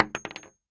Bullet Shell Sounds
rifle_wood_7.ogg